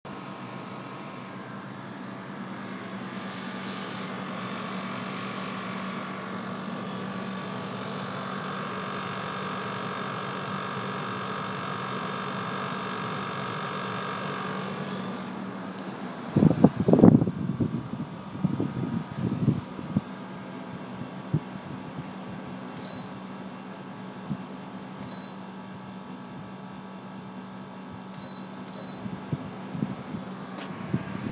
Bref depuis il y a un bruit beaucoup plus élevé ressemblant aux vieux congélateurs.
Oui elle fait bien du froid, ce qui m’inquiète c'est que le bruit "gênant" et typique d'un compresseur de frigo d'avant guerre mal réglé.
Ça ressemble un peu à un "tritritritritritritr" un peu aigu si ça peut aider.
Comme convenu je vous envoie l’enregistrement de ma clim Remko 3,2 kW en mp3.
On entend une variation dans la couleur du son entre les secondes 2 et 12, mais est-ce celle que vous constatez, ou est-ce parce que vous rapprochez le micro de l'appareil?
D'après ce que j'entends, cela ressemble à une vibration qui viendrait par dessus le bruit du compresseur et du ventilateur.
son-clim.mp3